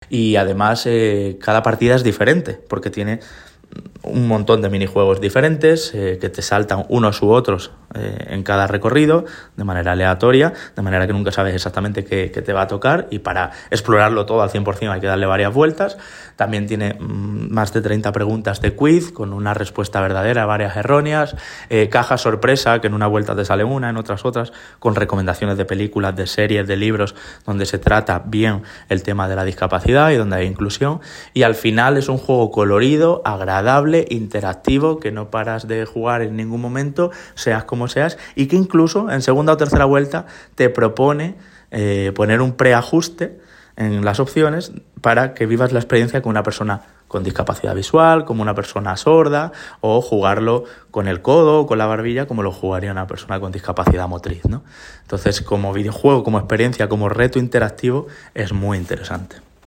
periodista especializado en videojuegos y accesibilidad.